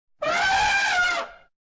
Звук слона, трубящего хоботом